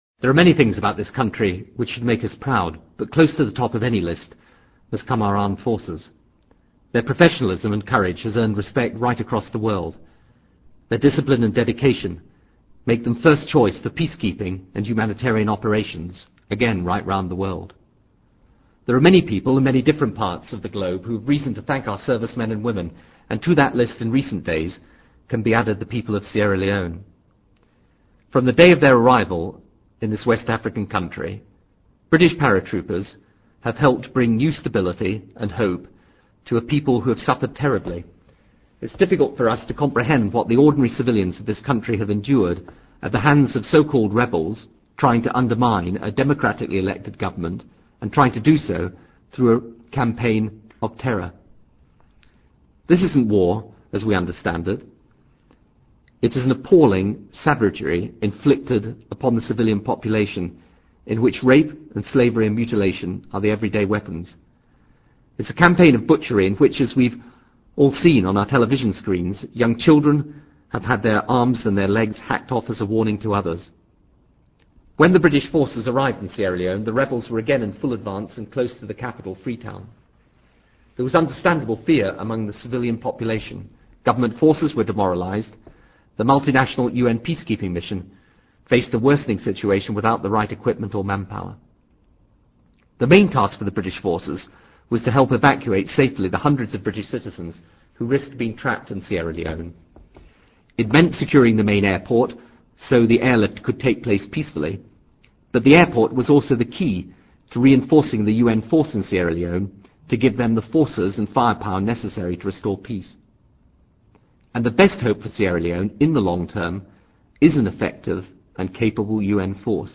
布莱尔首相演讲:Sierra Leone
Britain's role in Sierra Leone: Prime Minister's broadcast, Friday 19 May 2000